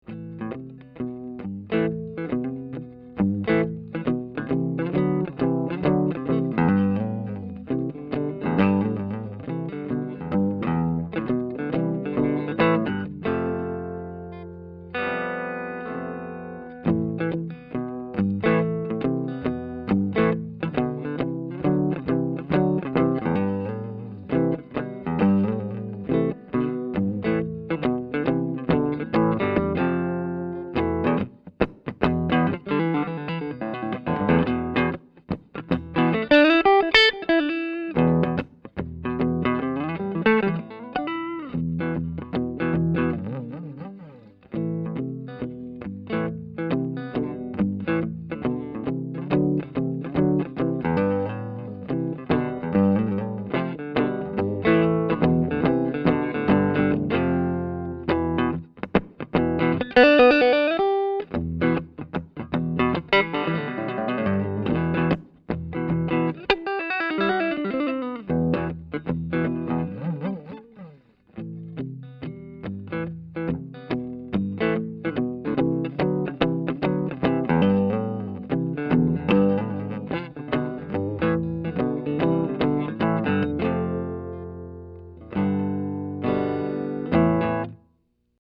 These tracks are just straight signal with no additional EQ, compresson or effects:
Here are an additional 9 MP3s of this same guitar tracked using a Lee Jackson Master Series amp and a Pearlman TM-LE tube mic, through the same signal chain:
EASTMAN / LEE JACKSON AMP